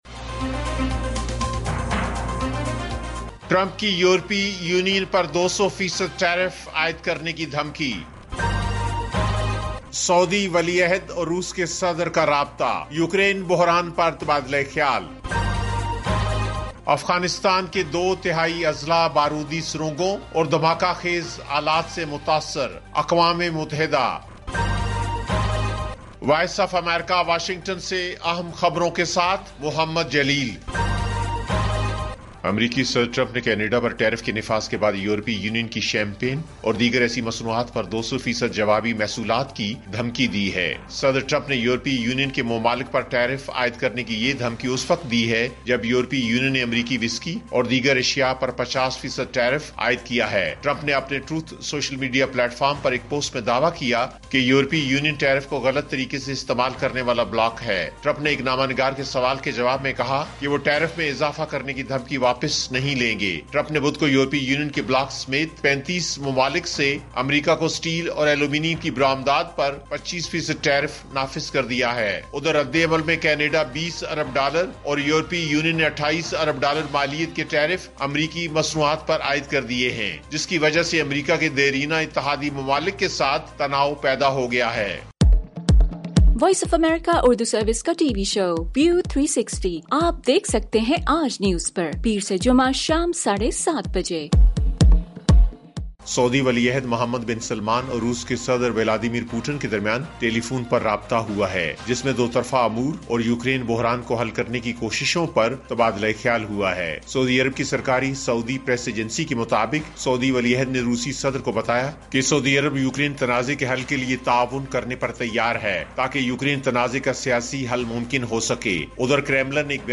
ایف ایم ریڈیو نیوز بلیٹن: شام 7 بجے